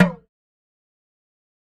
MD (Flange).wav